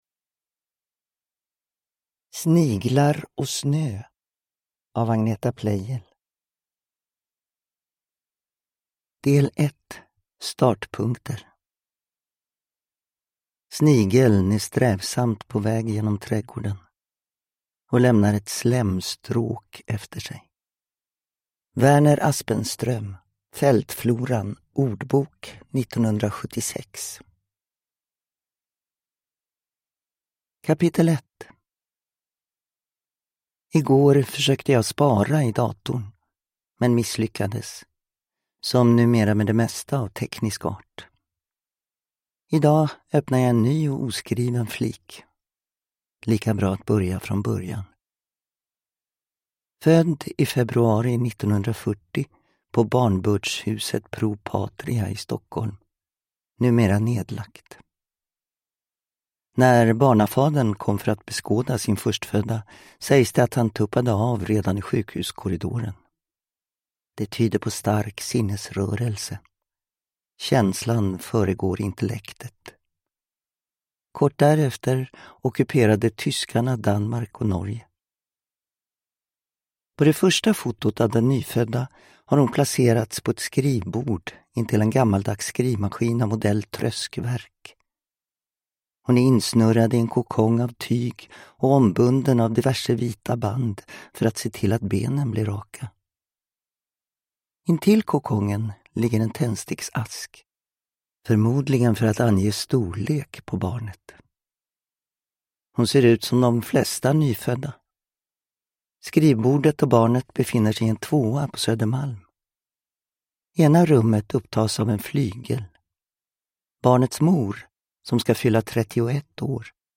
Uppläsare: Gunnel Fred